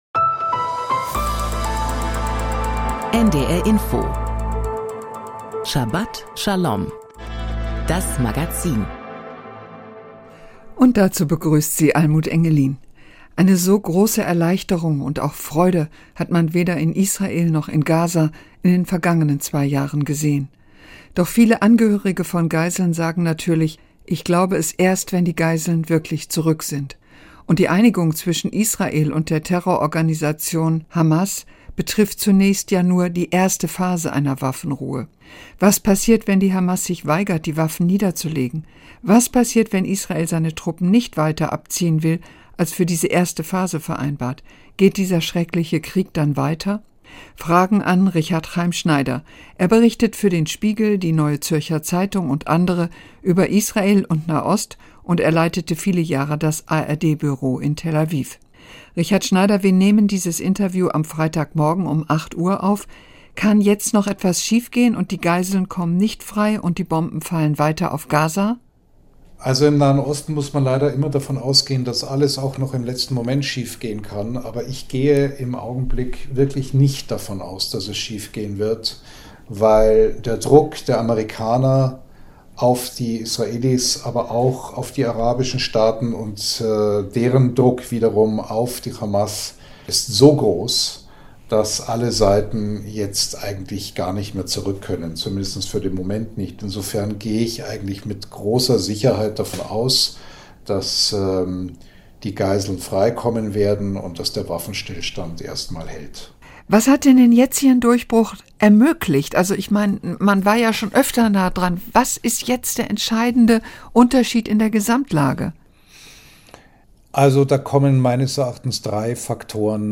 Interview mit Richard C. Schneider über das Abkommen zur Waffenruhe in Gaza und Israel
Thora-Auslegung